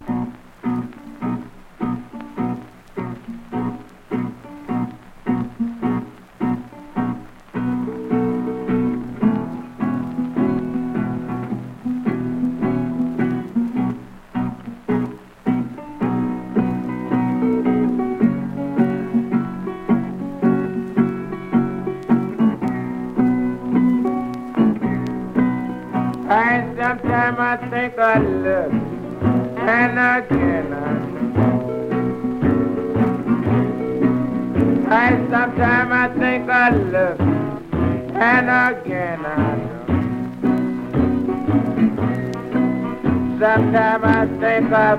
Blues, Country Blues, Jug Band, Jazz　USA　12inchレコード　33rpm　Mono